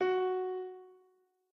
piano.ogg